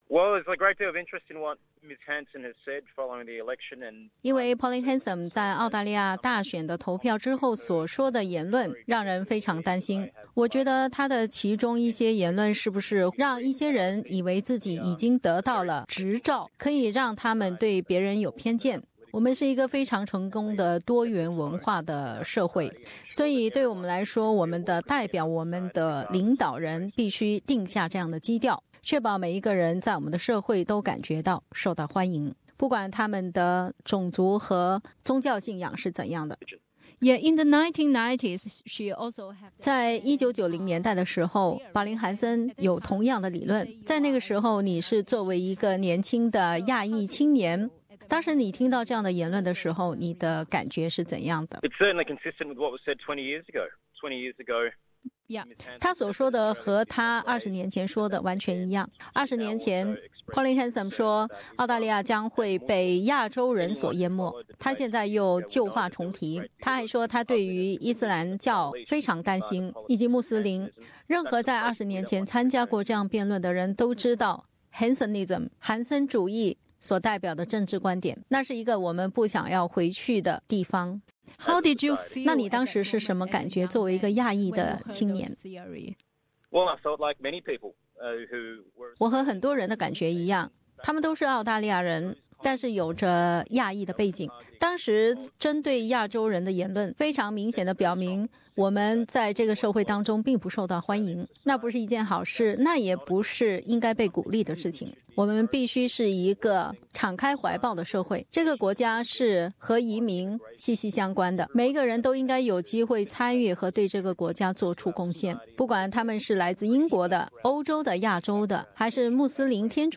他邀请韩森女士到悉尼西区，和他一起吃一碗越南粉，或是尝尝猪肉卷，以事实为基础，有理性地讨论难民、移民等问题。 反种族歧视专员Tim Soutphommasane在出租车上接受了本台记者的连线采访，他说，他这几天之所以如此忙碌，是因为韩森女士的言论引发了他的担忧。